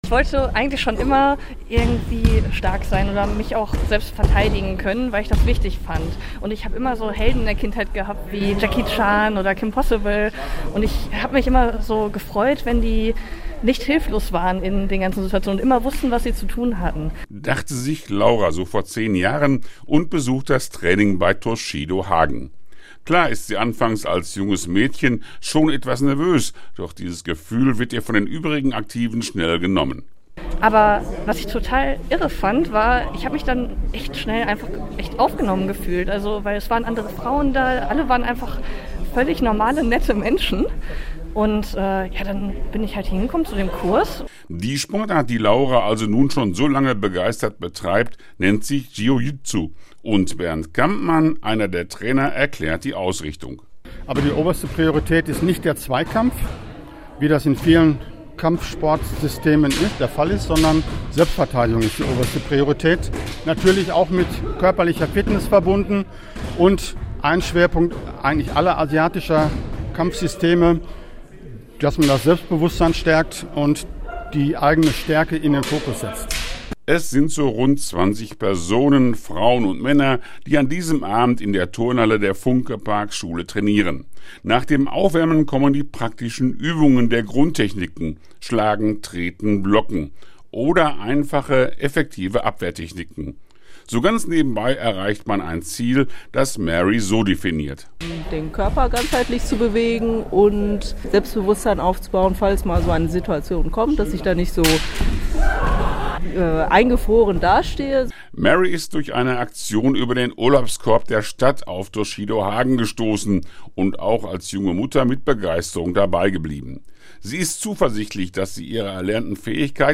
als er eine Trainingseinheit bei Toshido Hagen besuchte.